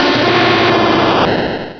Cri de Typhlosion dans Pokémon Rubis et Saphir.